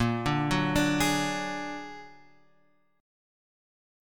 A# Augmented 7th